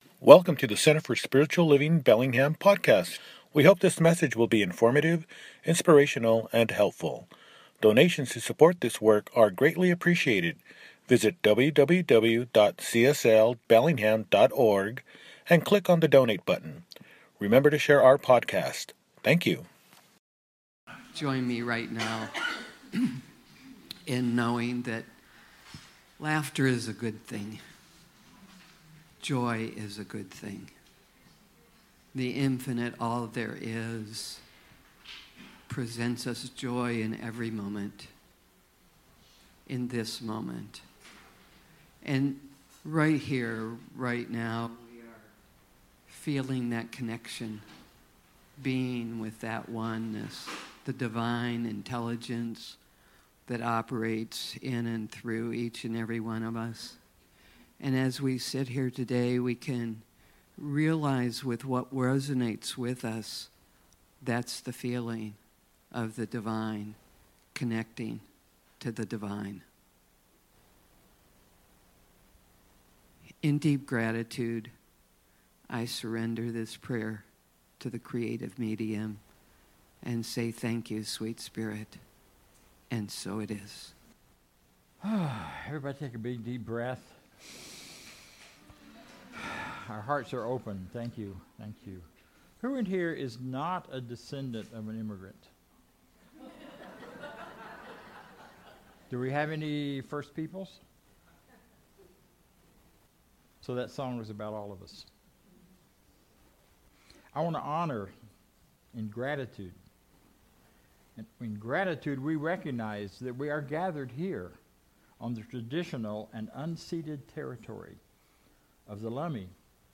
On Whose Shoulders We Stand – Celebration Service